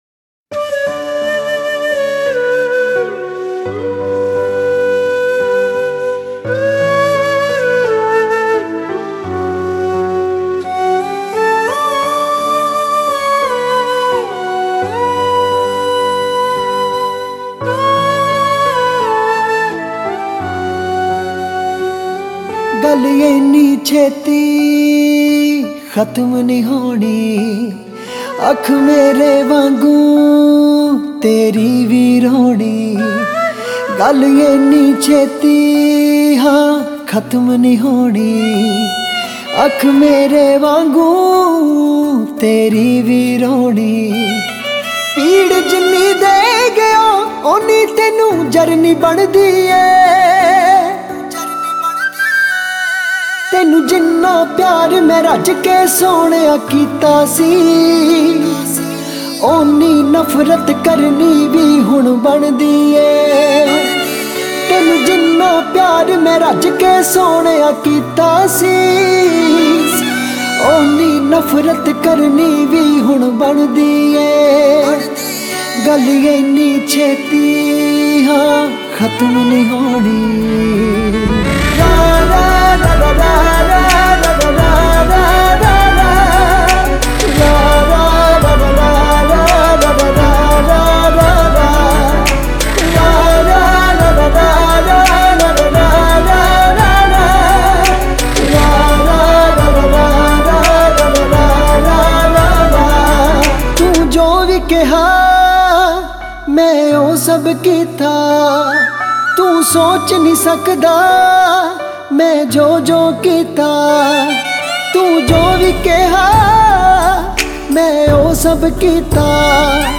Song Genre : Latest Punjabi Songs